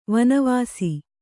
♪ vana vāsi